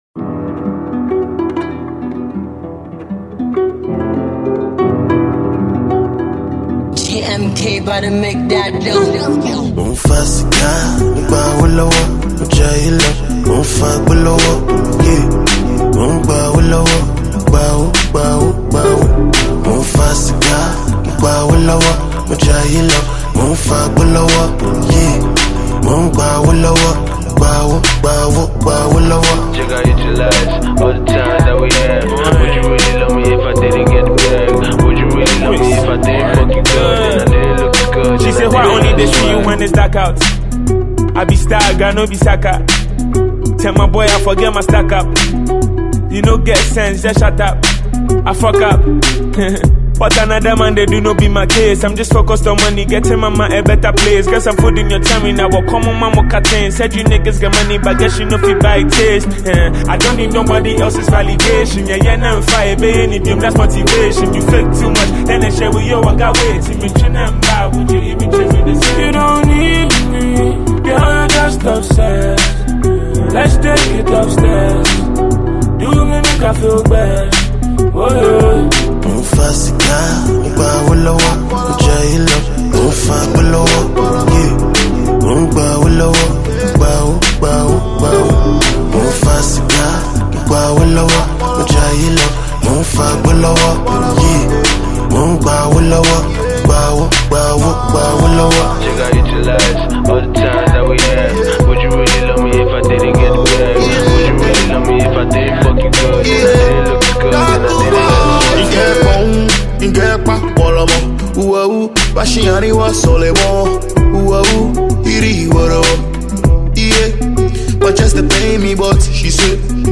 bop
Ghanaian rapper
Sensational Afrobeat/Afropop artiste